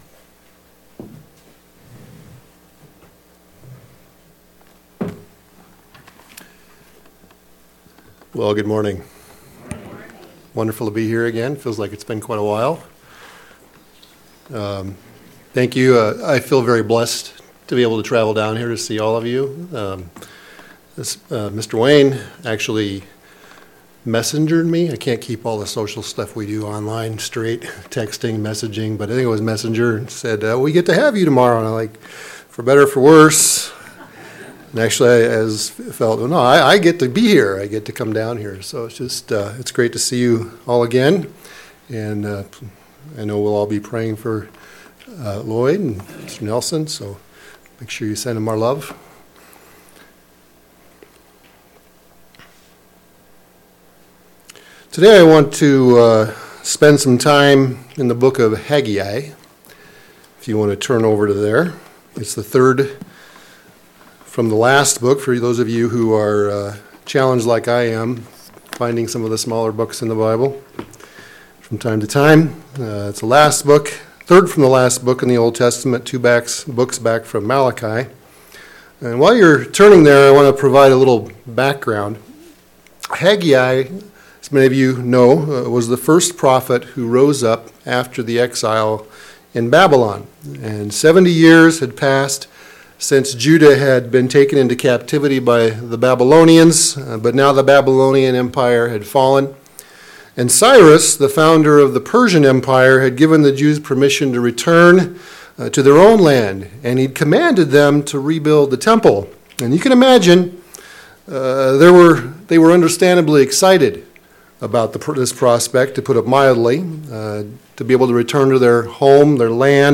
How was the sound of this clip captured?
Given in Southern Minnesota